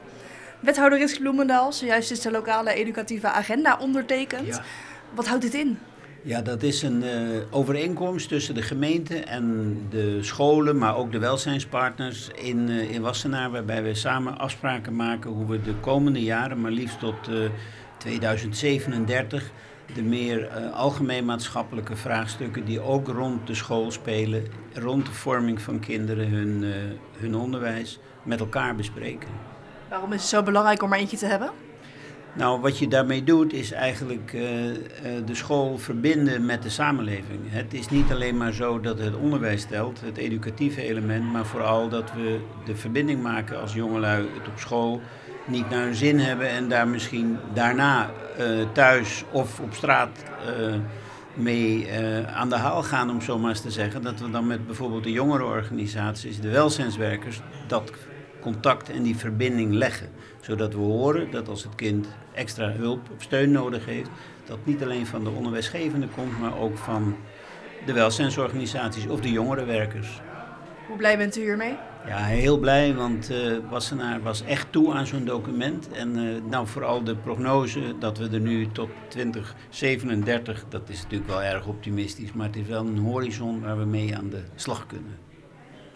in gesprek met wethouder Ritske Bloemendaal.